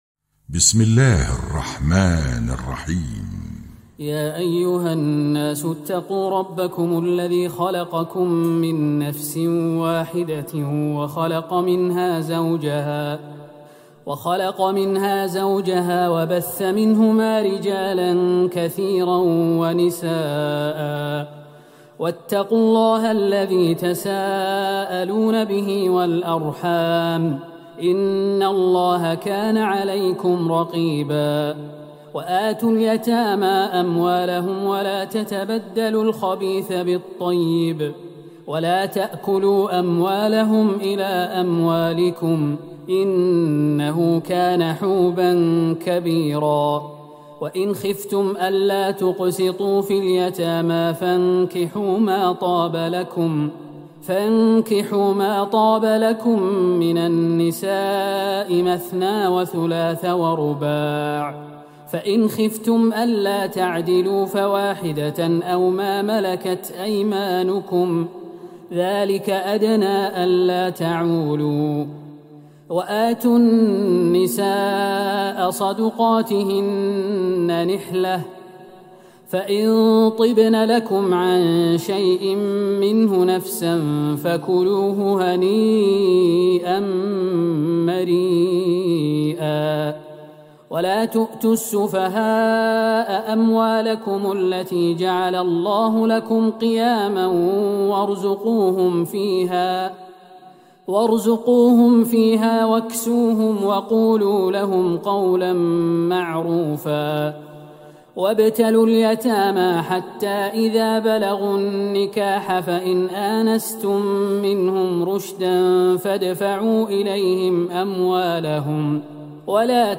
ليلة ٦ رمضان ١٤٤١هـ من سورة النساء { ١-٤٢ } > تراويح الحرم النبوي عام 1441 🕌 > التراويح - تلاوات الحرمين